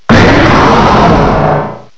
sovereignx/sound/direct_sound_samples/cries/tyranitar_mega.aif at master
tyranitar_mega.aif